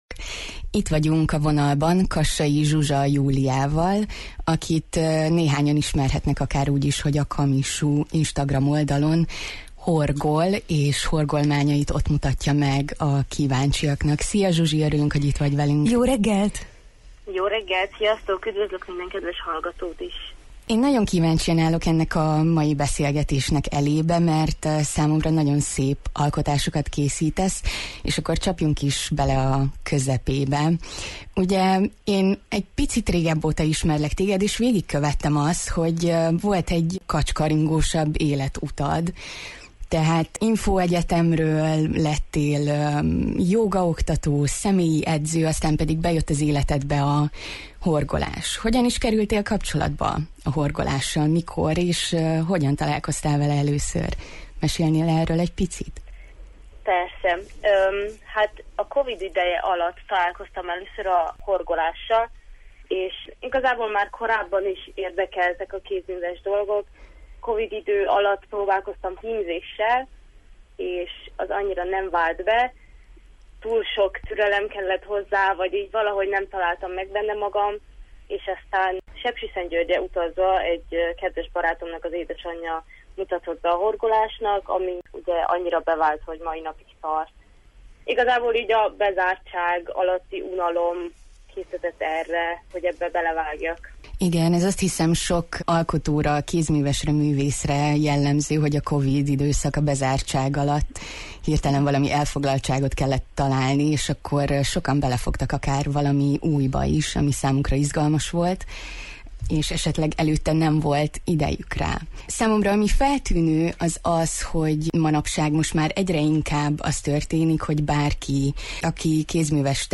Hogy milyen úton kezdődött meghívottunk kapcsolata a horgolással, illetve milyen formában igyekszik ezt átadni a kíváncsi érdeklődők számára, megtudhatják beszélgetésünkből.